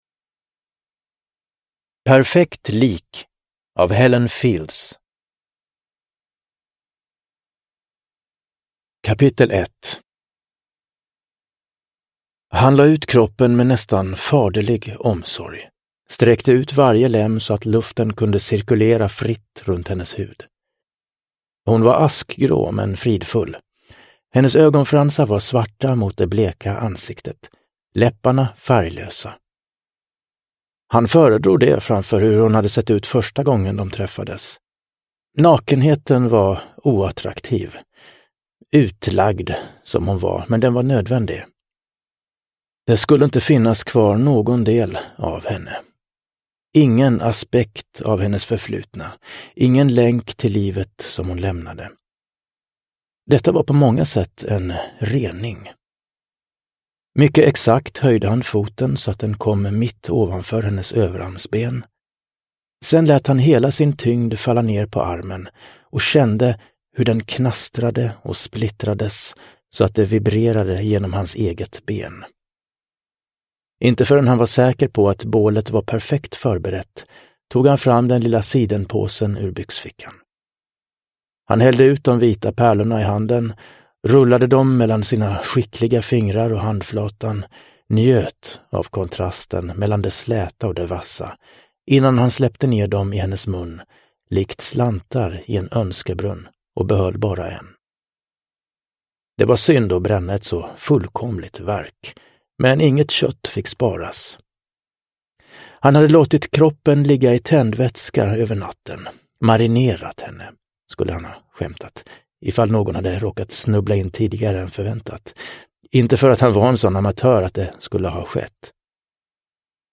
Perfekt lik – Ljudbok – Laddas ner